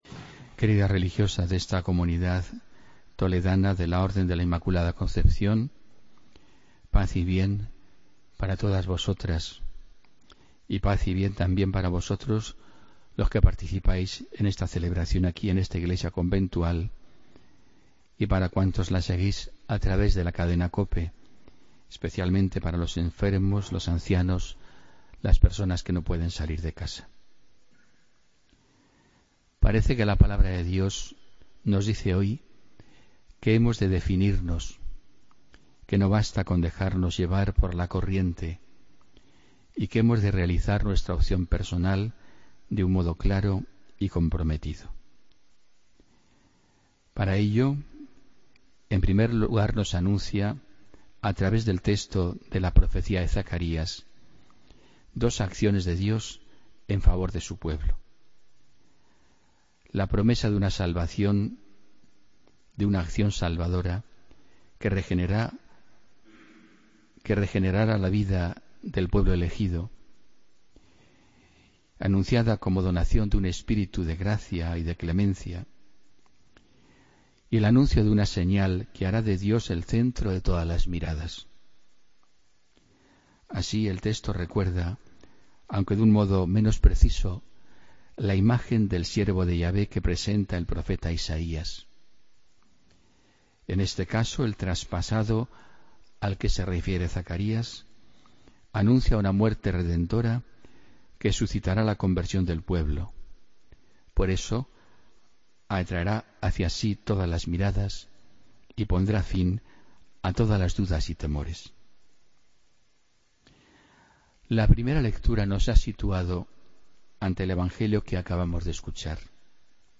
Homilía del domingo 19 de junio de 2016